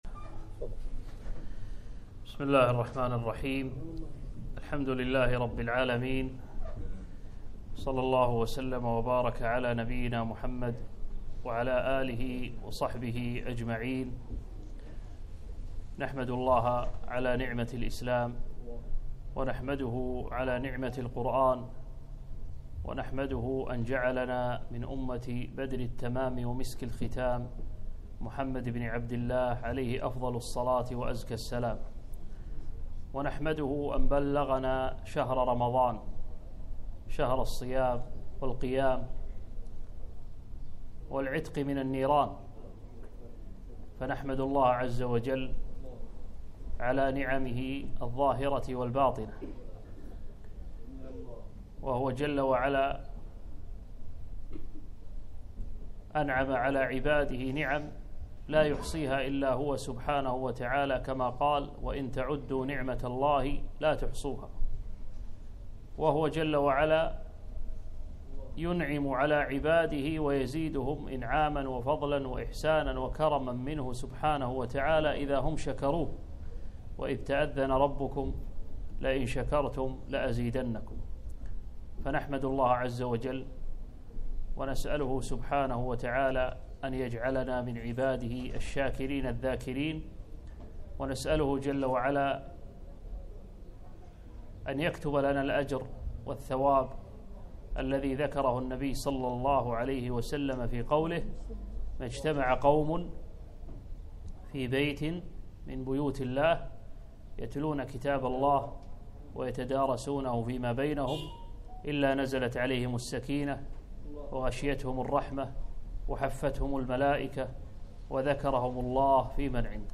كلمة - حقيقة التقوى